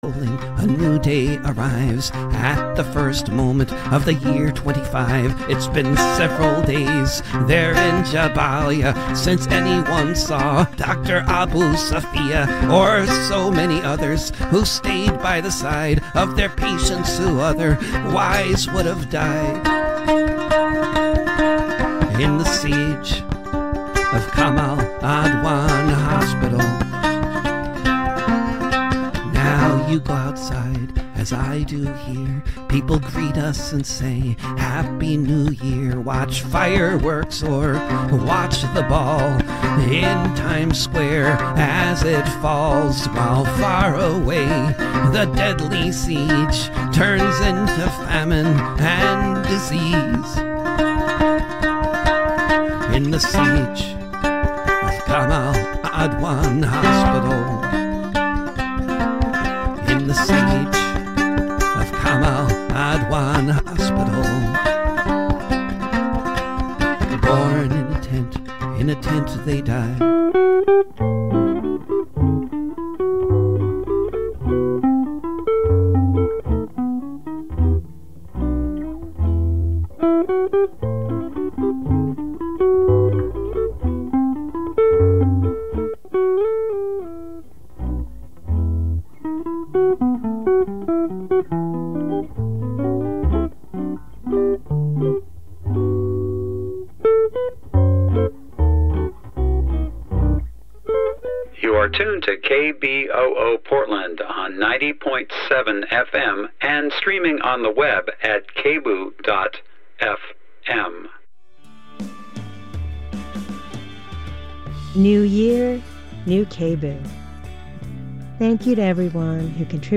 Views, Reviews, and Interviews